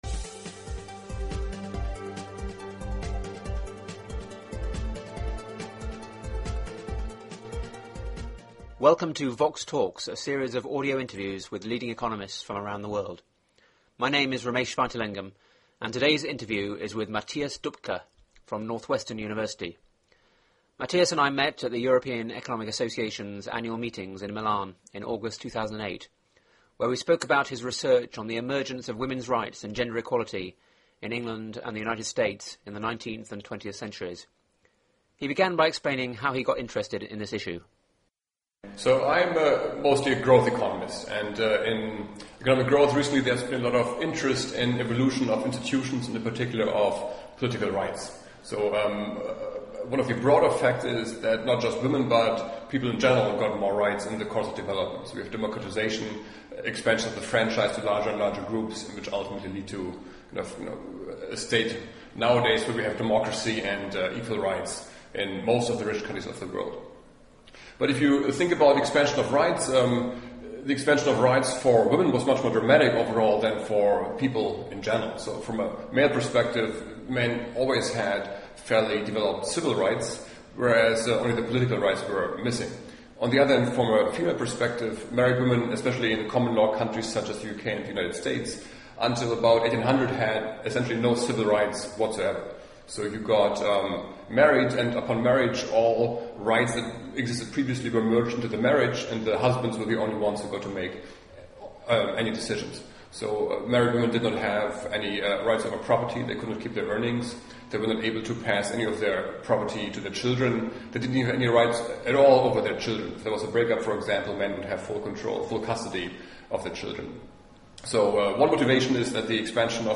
The interview was recorded at the annual congress of the European Economic Association in Milan in August 2008.